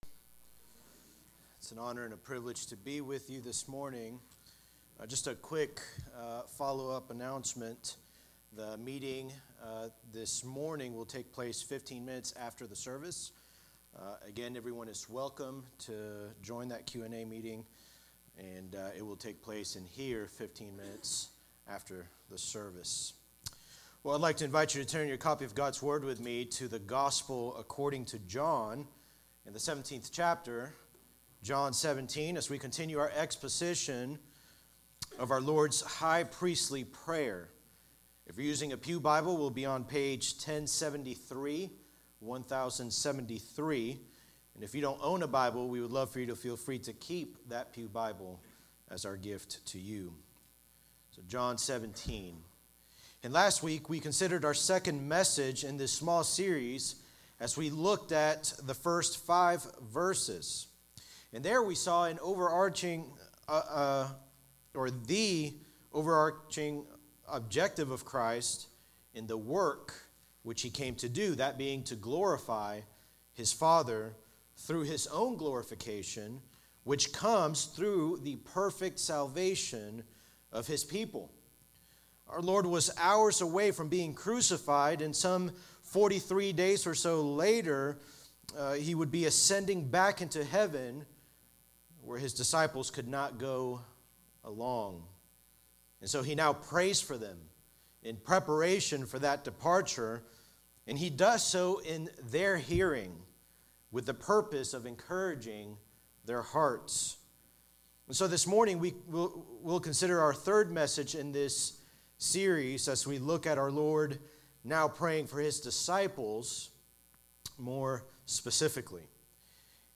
Sermons by Eatonville Baptist Church EBC